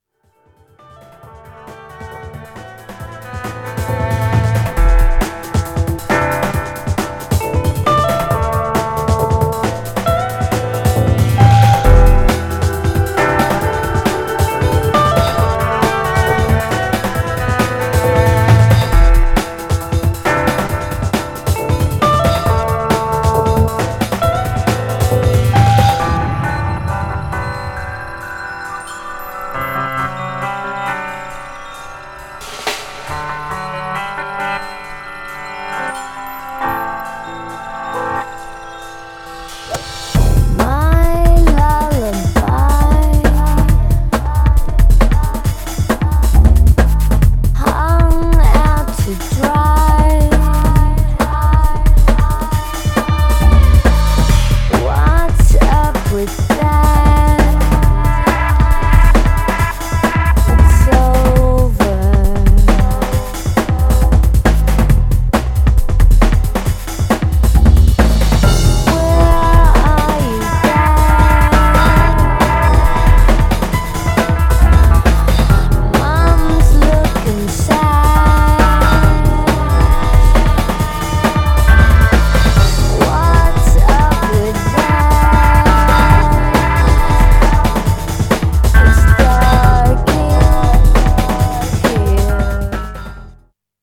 Styl: Breaks/Breakbeat